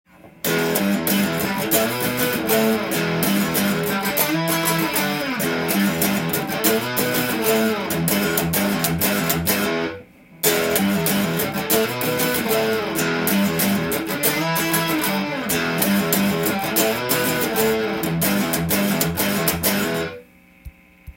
Emペンタトニックスケールで例を作ってみました
③のリフは開放弦の低音弦にオクターブ奏法を
オクターブ奏法が入ることで明るい雰囲気が出ますので